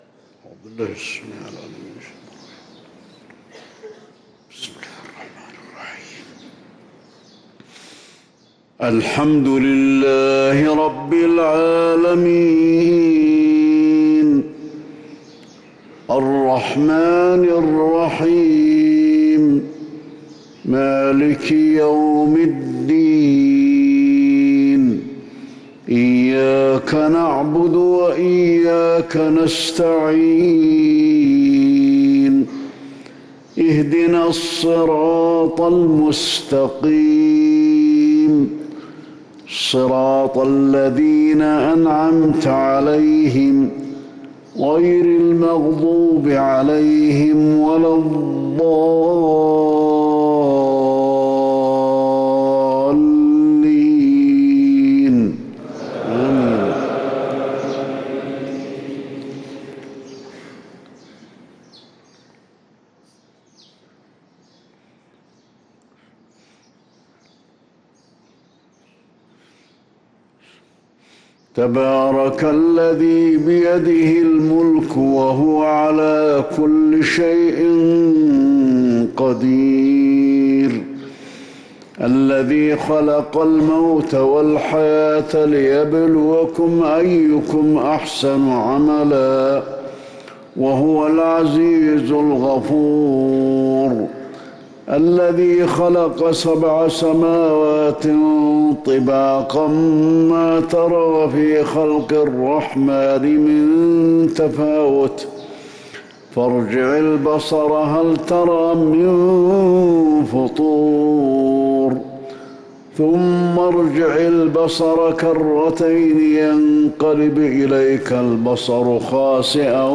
صلاة الفجر للقارئ علي الحذيفي 28 ربيع الأول 1445 هـ